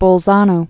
(bōl-zänō, -tsä-)